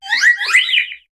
Cri de Flabébé dans Pokémon HOME.